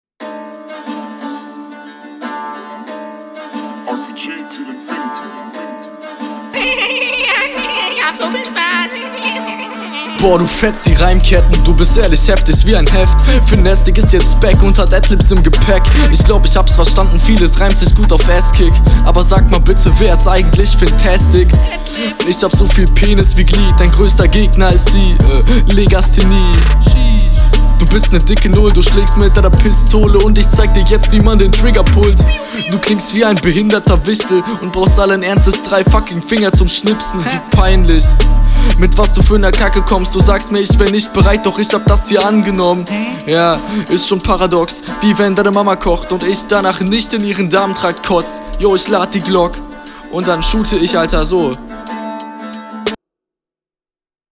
Rappst auf jeden lässig über den Beat direkt, gefällt mir.
attitude kommt bei dir viel cooler. du rapst musikalischer und das kommt alles einfach nice …